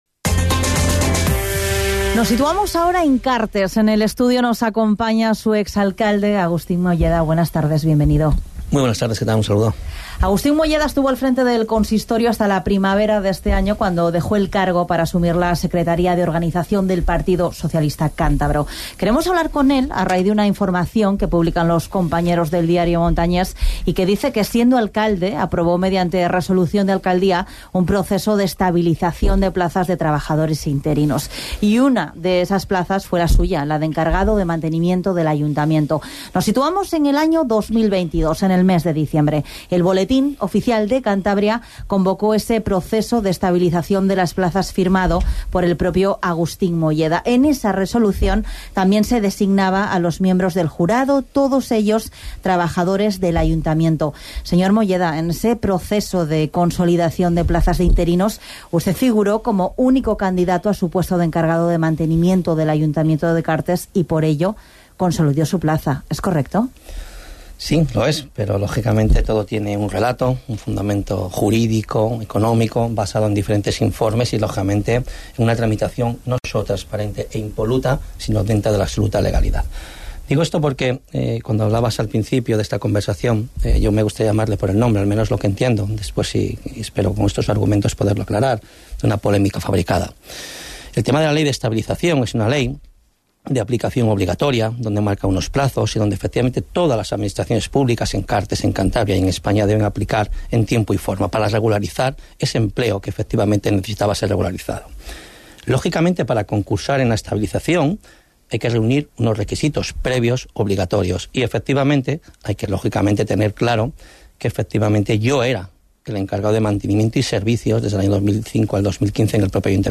El exalcalde de Cartes, Agustín Molleda, ha salido al paso de las informaciones que cuestionan el proceso mediante el cual consolidó su plaza como encargado de mantenimiento en el Ayuntamiento. En una entrevista concedida a la Cadena SER, Molleda ha defendido la legalidad, transparencia y necesidad del procedimiento, enmarcado en la ley estatal de estabilización de empleo público.